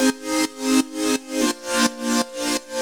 GnS_Pad-MiscA1:4_170-C.wav